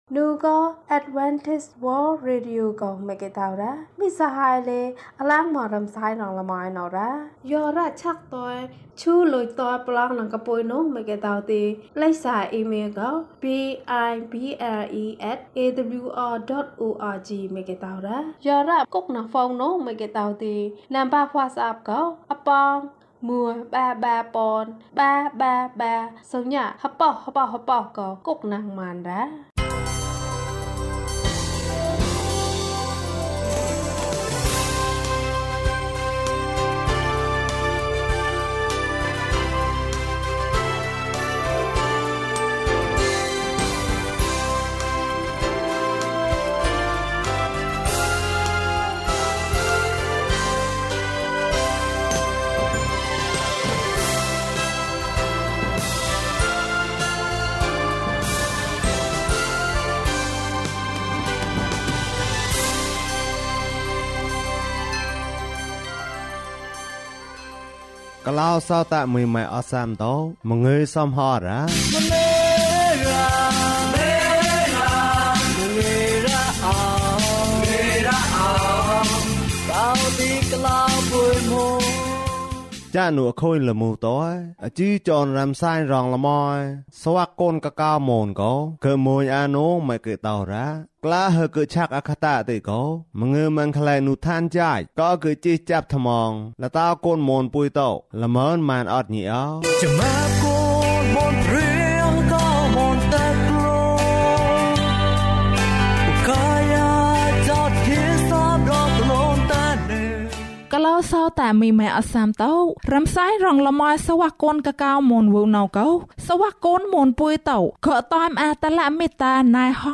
ဘုရားသခင် ငါ့ကို ကယ်တင်ပါ။ ကျန်းမာခြင်းအကြောင်းအရာ။ ဓမ္မသီချင်း။ တရားဒေသနာ။